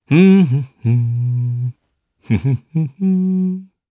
Clause-Vox_Hum_jp.wav